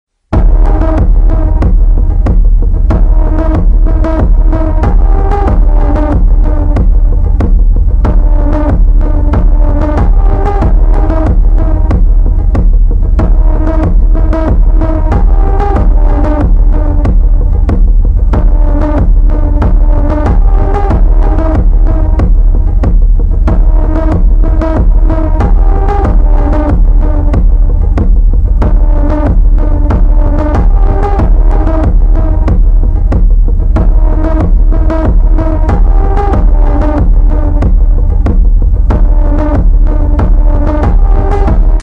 Crash Test A New Car Sound Effects Free Download